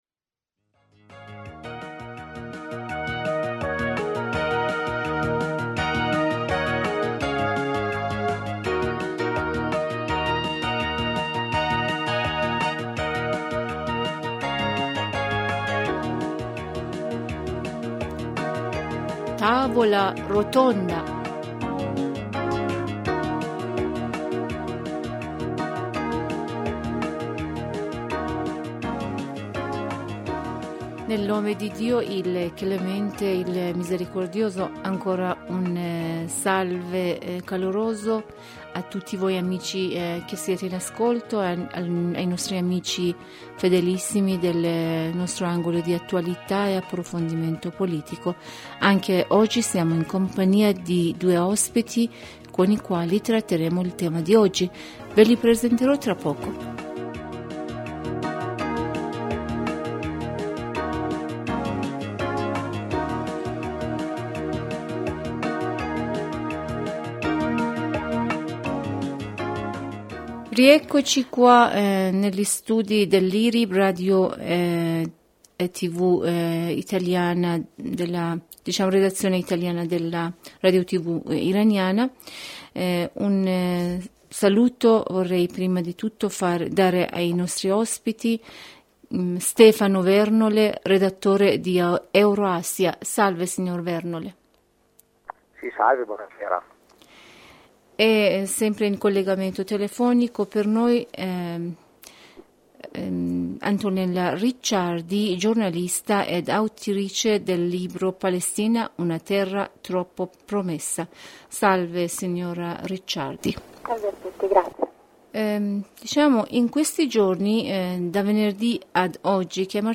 Quarta_intervista_IRIB.mp3